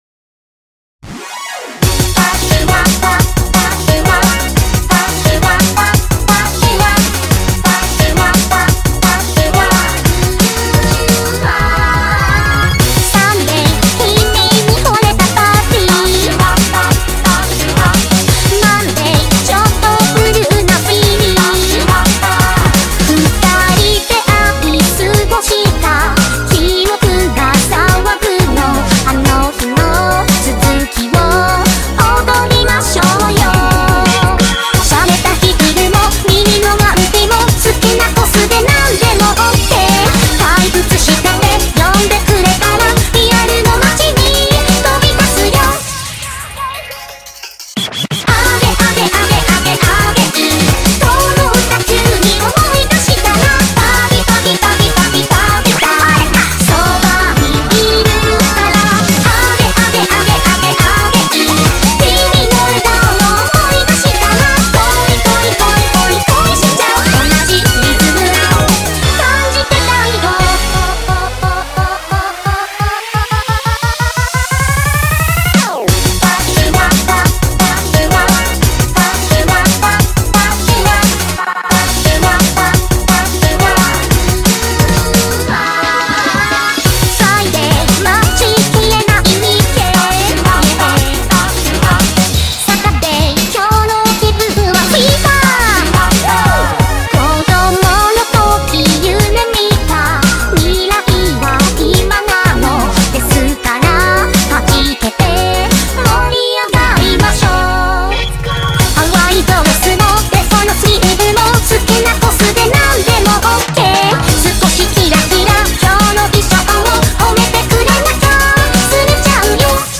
BPM175
Audio QualityCut From Video